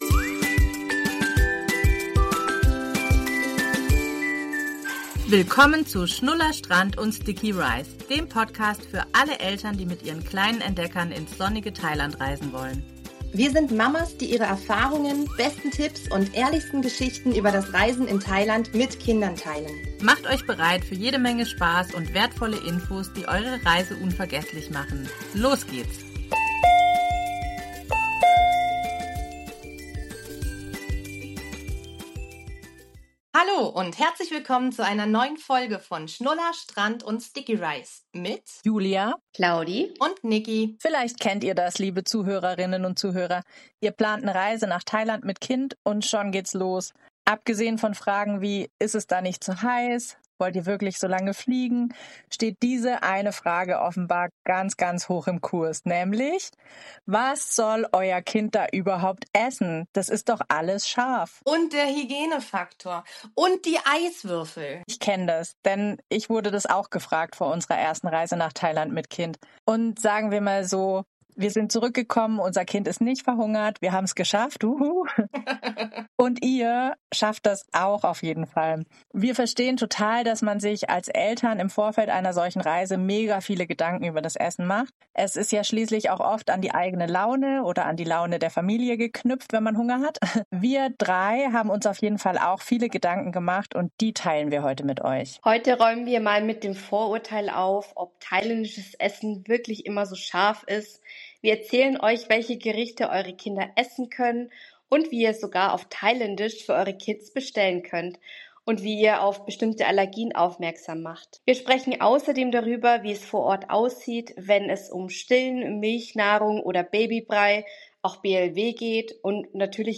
zwei Mamas mit Fernweh, Sonnencreme im Gepäck und ganz viel Herz für Thailand.